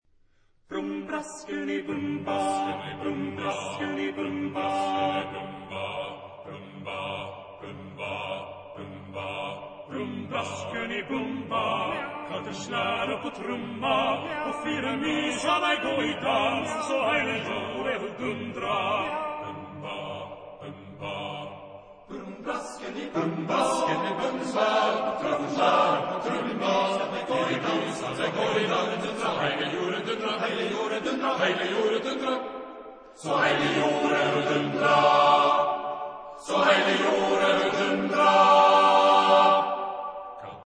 Genre-Style-Forme : Romantique ; Profane ; Lied
Type de choeur : TTBB  (4 voix égales d'hommes )
Solistes : Baryton (1)  (1 soliste(s))
Tonalité : sol majeur